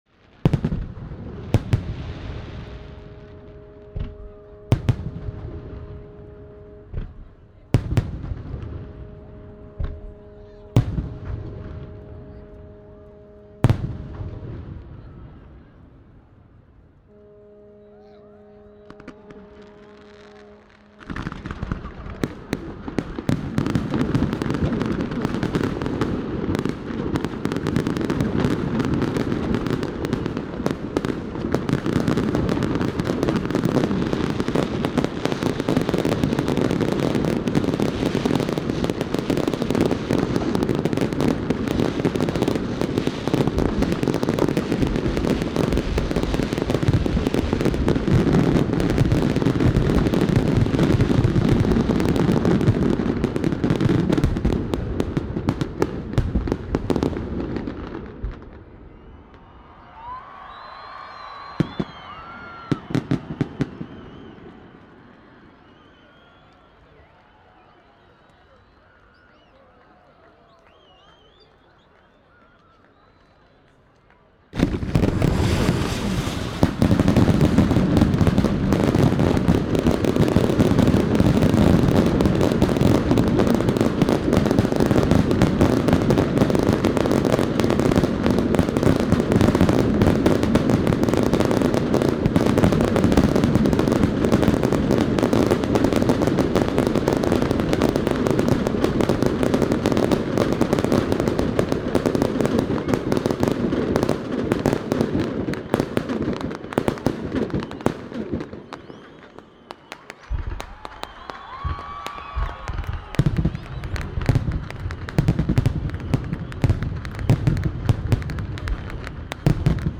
Fireworks at Culture Night 2015
Recording fireworks can be difficult. The dynamic of the blast is normally far more than the equipment can record in full quality.
Any reflection or echo from nearby buildings or mountains can make the whole blasting thing as an outstanding symphony. Culture night has been held in August in Reykjavik for twenty years and it normally ends with huge down town rock concert and a firework show.
This year I spotted place nearby Harpa concert hall where two big buildings are on two sides.
flugeldar-mn-2015.mp3